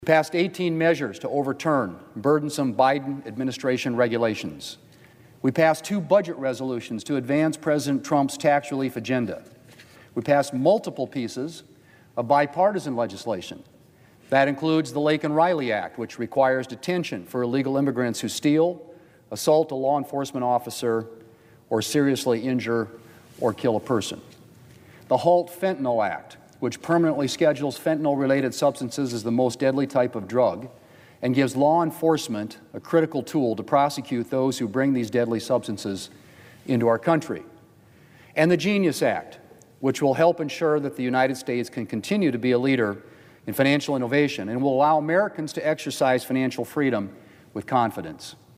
WASHINGTON, D.C.(HubCityRadio)- On Wednesday, Senate Majority Leader John Thune was on the floor of the U.S. Senate to reflect on the first six months of the session.